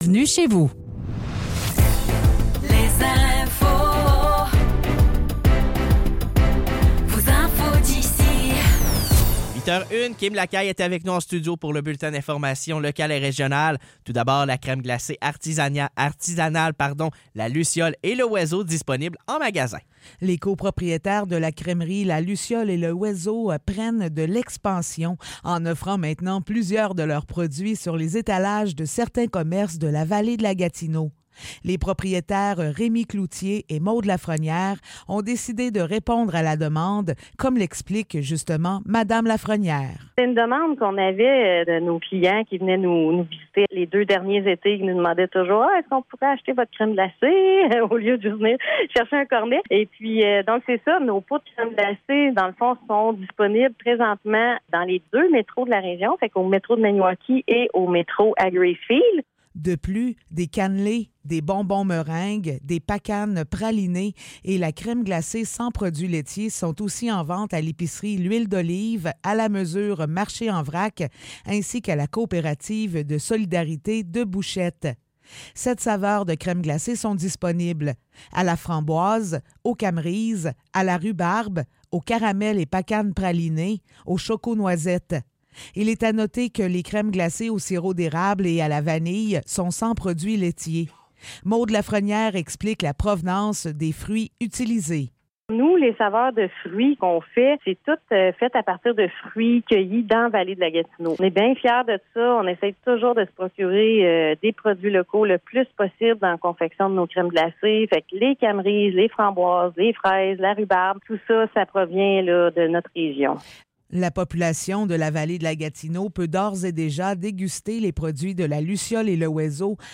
Nouvelles locales - 5 juin 2024 - 8 h